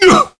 Gau-Vox_Damage_kr_01.wav